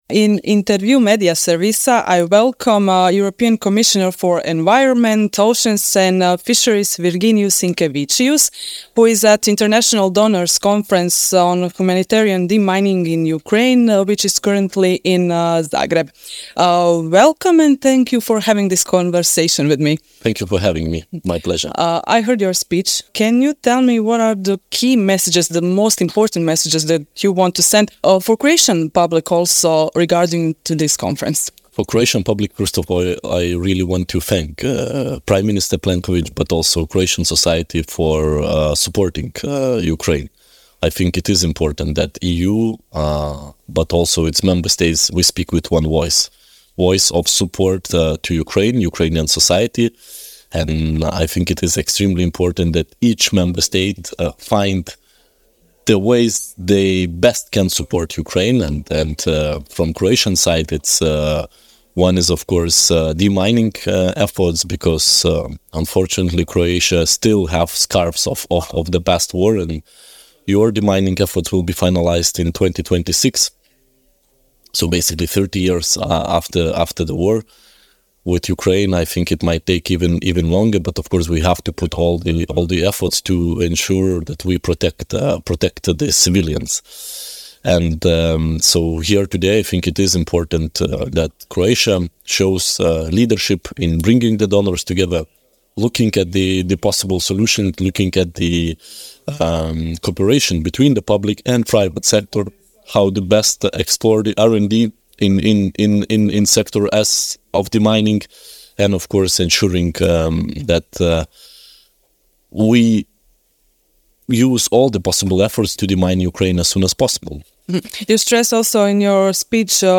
Jedan od govornika na jučerašnjem otvaranju prve Konferencije ovog tipa u Zagrebu bio je, između ostalih, povjerenik Europske komisije za okoliš, oceane i ribarstvo Virginijus Sinkevičius koji je tim povodom gostovao u Intervjuu Media servisa.